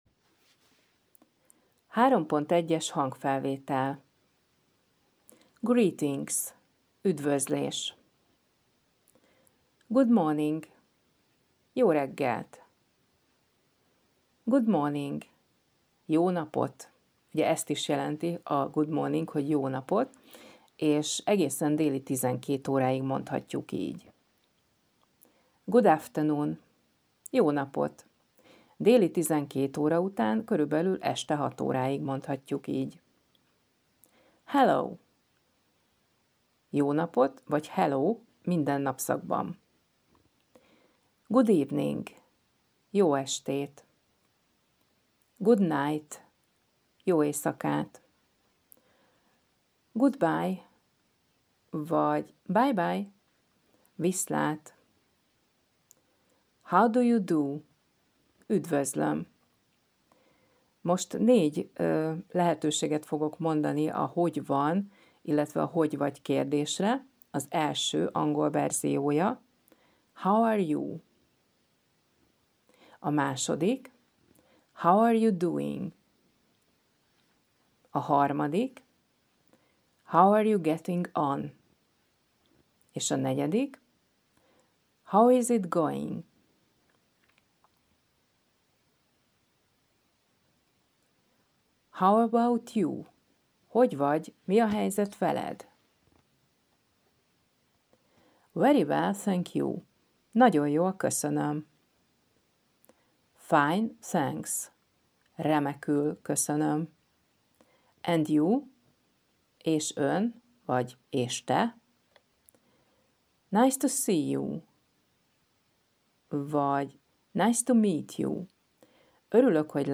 lassan, tagoltan, jól artikuláltan, kellemes hangon mondja el a szavakat, kifejezéseket, mondatokat